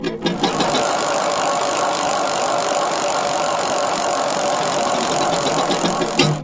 На этой странице представлены звуки колеса фортуны в разных вариациях: от классического вращения до эффектных фанфар при выигрыше.
Звук кручения ручки колеса фортуны